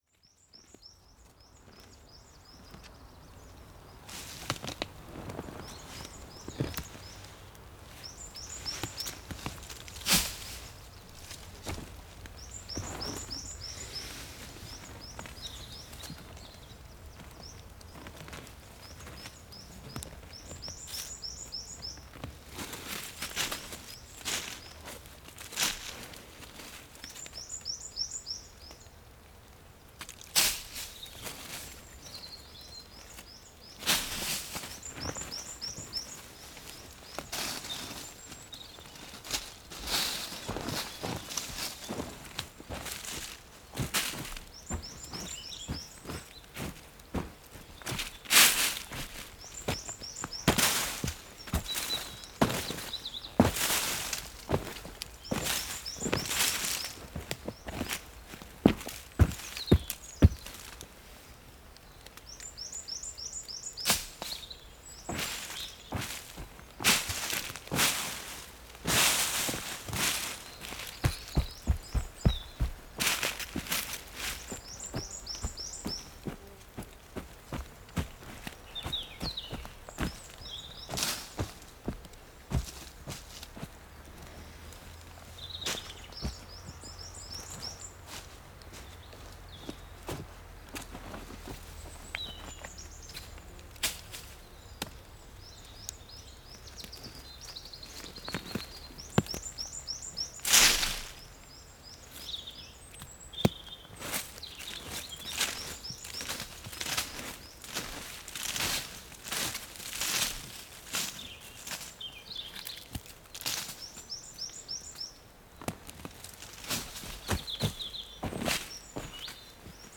Marcar passo frente a pinheiro no lugar de Azival em Cepões, Cepões a 24 Março 2016.